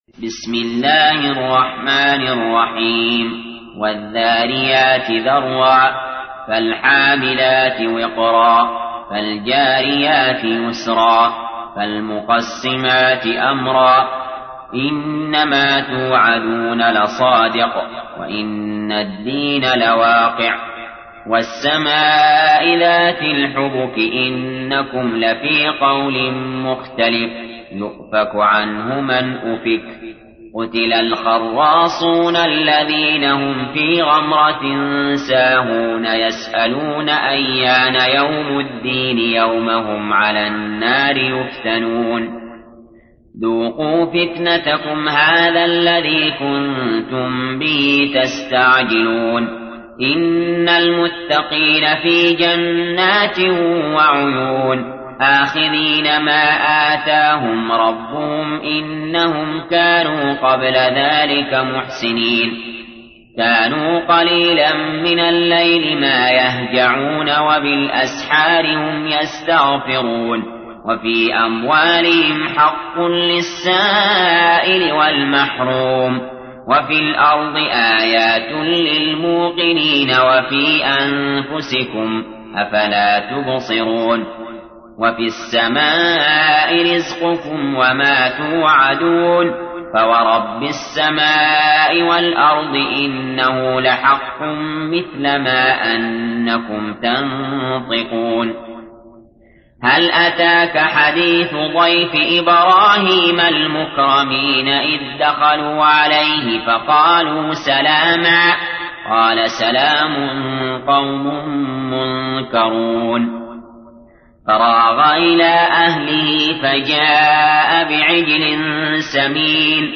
تحميل : 51. سورة الذاريات / القارئ علي جابر / القرآن الكريم / موقع يا حسين